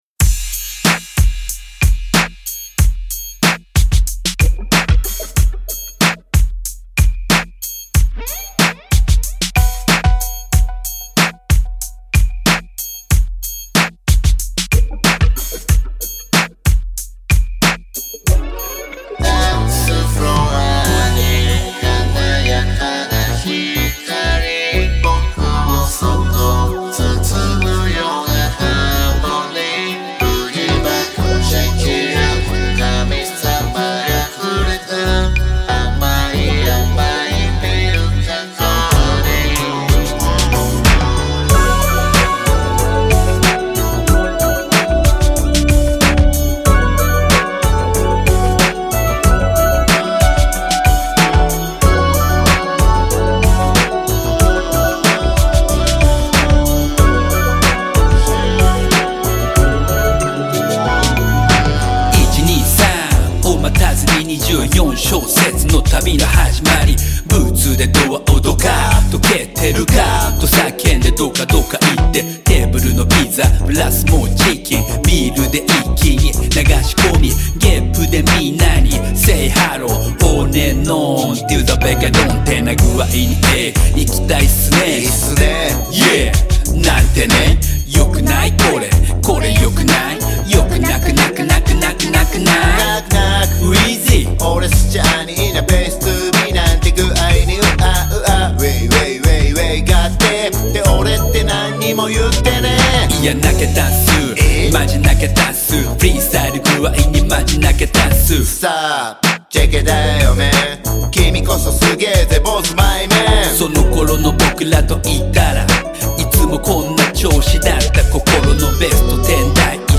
秋も冬も"ごっさファンキーなベースライン"もGファンク！！
ジャンル(スタイル) JAPANESE HIP HOP